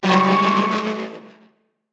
moto_skid.ogg